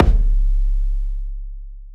sub_li_kick.wav